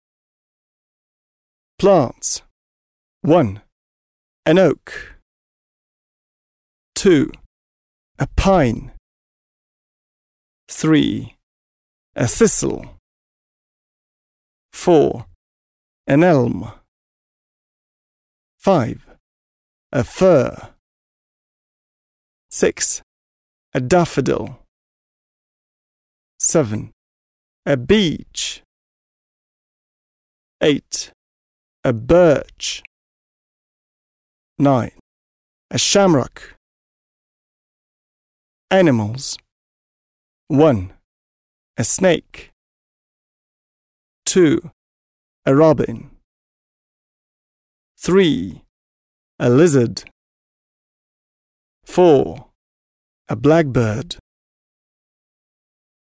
an oak [‘əʊk] [эук] — дуб
a thistle [‘θɪsl] [сисл] — чертополох